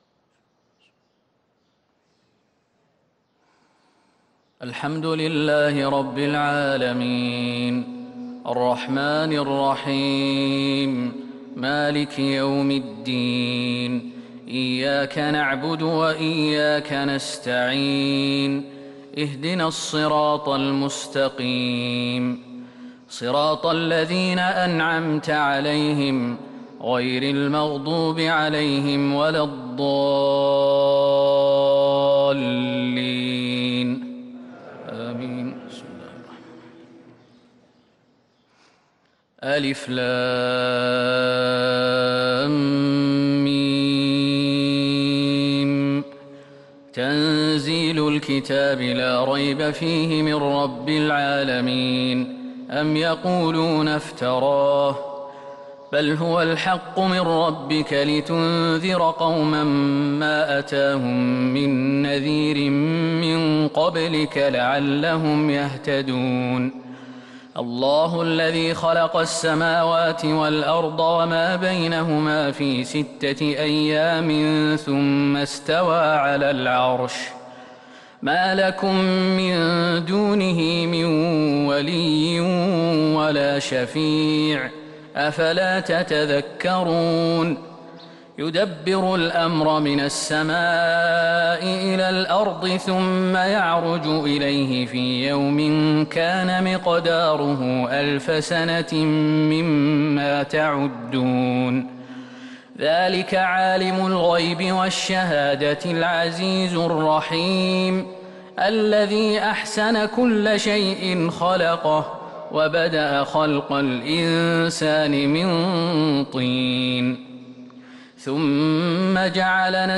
فجر الجمعة 1-8-1443هـ سورتي السجدة و الإنسان | Fajr prayer Surat Alsajdah and Alinsan 4-3-2022 > 1443 🕌 > الفروض - تلاوات الحرمين